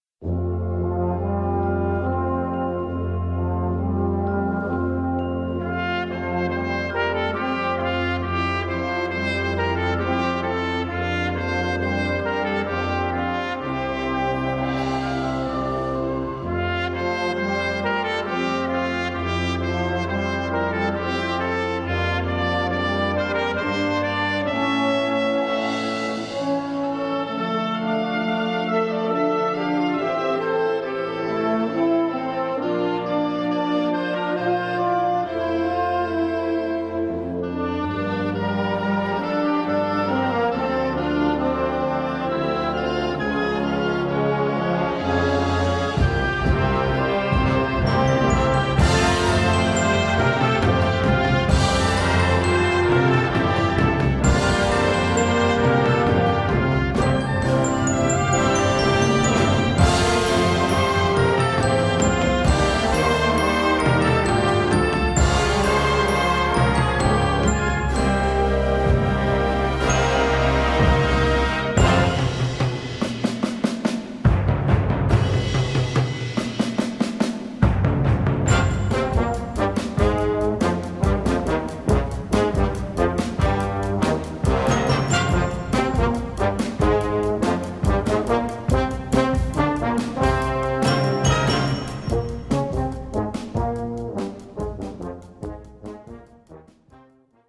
Kategorie Blasorchester/HaFaBra
Unterkategorie Ouvertüre (Originalkomposition)
Besetzung Ha (Blasorchester)
Schwierigkeitsgrad 3